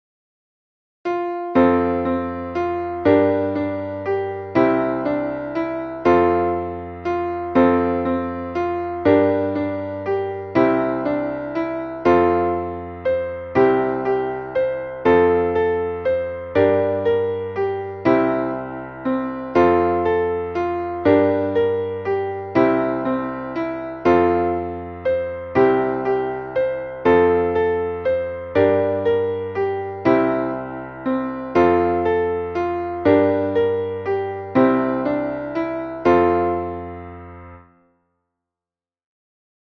Traditionelles Neujahrslied